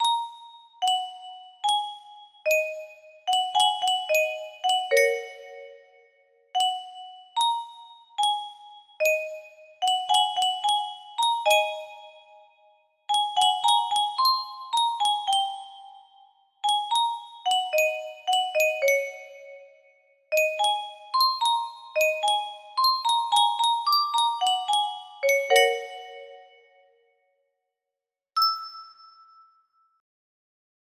a simple version of a common, and fairly old melody